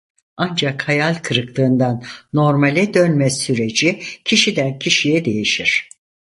Pronúnciase como (IPA)
/dœnˈme/